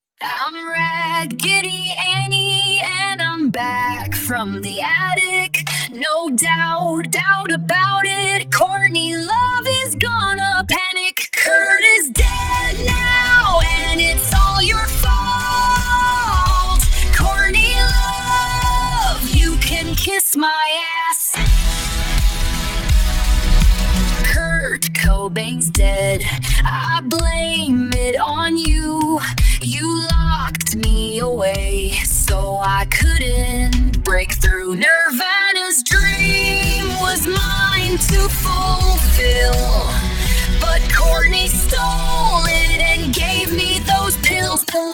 annie_folk2.mp3